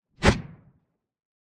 punch_long_whoosh_21.wav